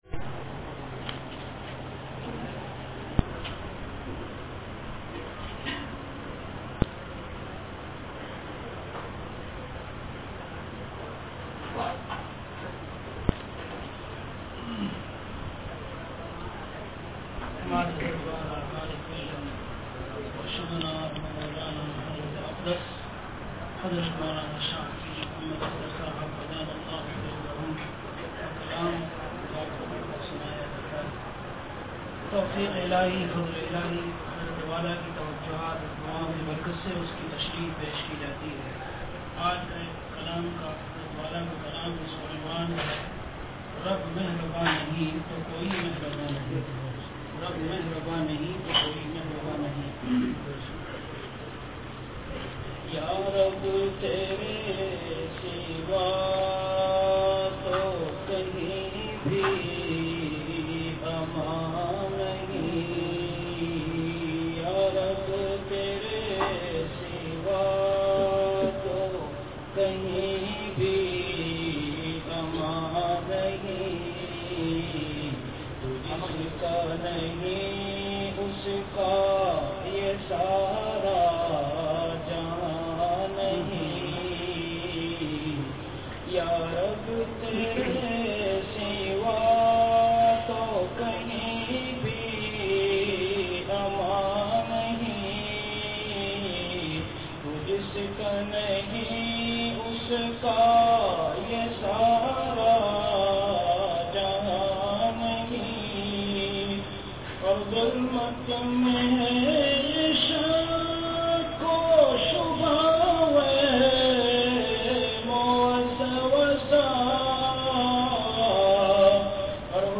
An Islamic audio bayan
Delivered at Khanqah Imdadia Ashrafia.